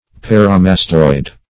Search Result for " paramastoid" : The Collaborative International Dictionary of English v.0.48: Paramastoid \Par`a*mas"toid\, a. [Pref. para- + mastoid.]